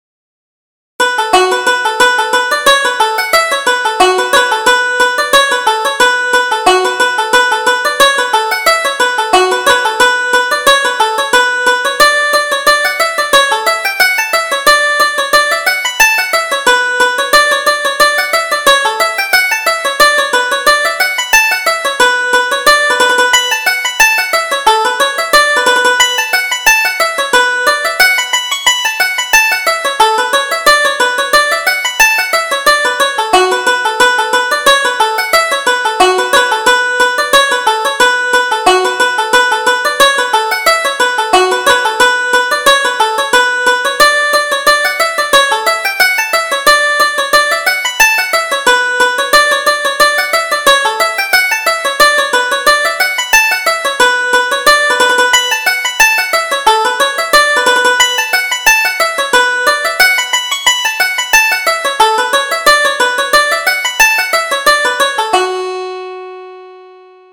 Reel: The Musical Priest